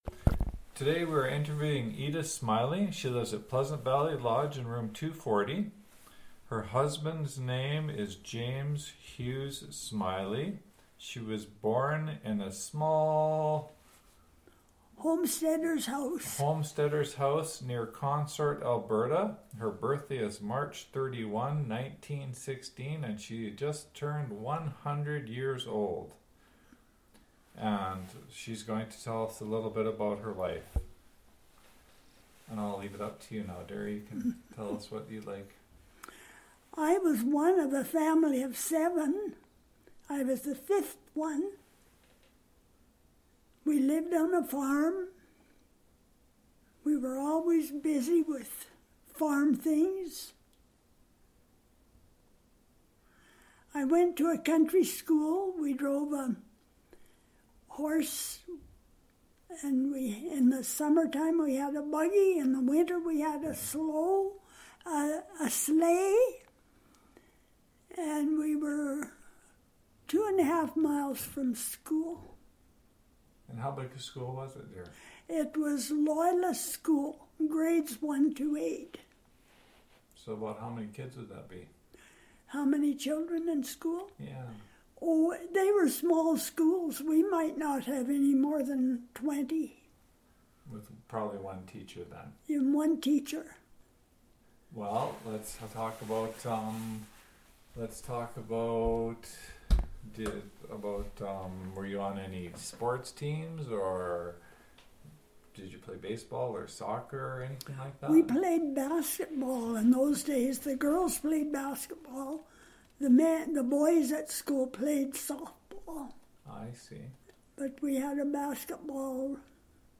Audio, Transcript of audio interview and Obituary,